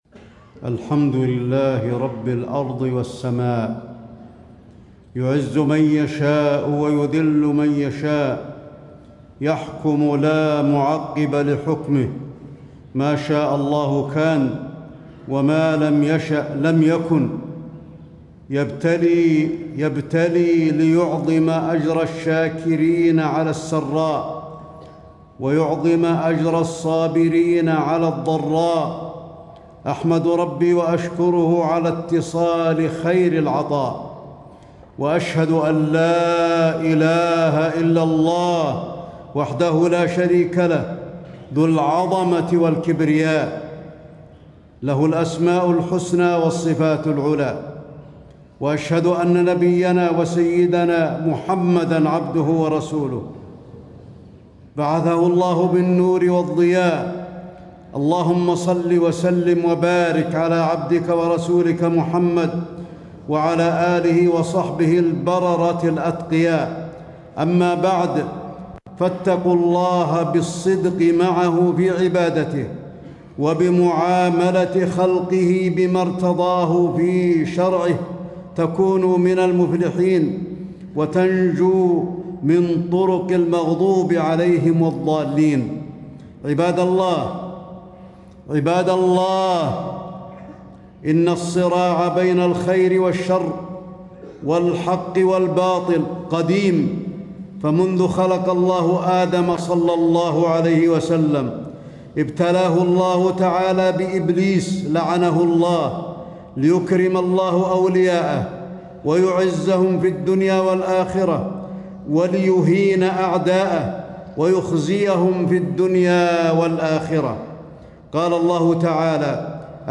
تاريخ النشر ١٤ جمادى الآخرة ١٤٣٦ هـ المكان: المسجد النبوي الشيخ: فضيلة الشيخ د. علي بن عبدالرحمن الحذيفي فضيلة الشيخ د. علي بن عبدالرحمن الحذيفي حماية اليمن من المفسدين